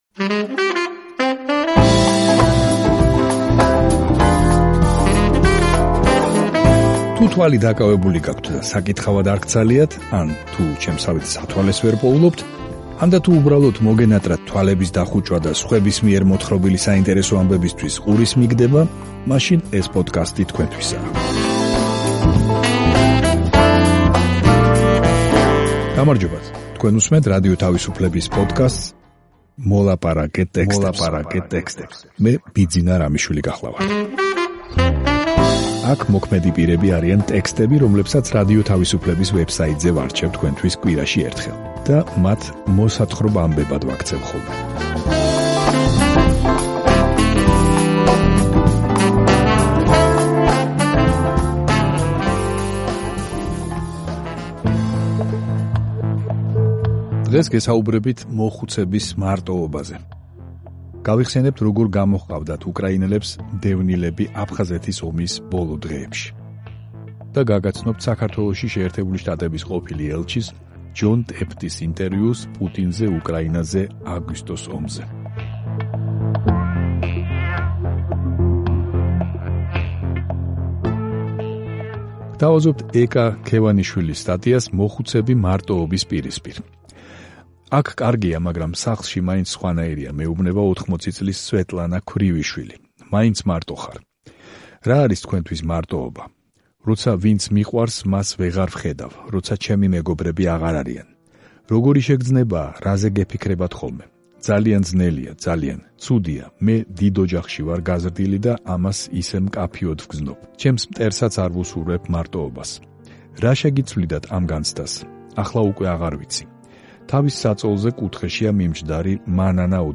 მოხუცების მარტოობა. უკრაინელები აფხაზეთის ომში. საუბარი ჯონ ტეფტთან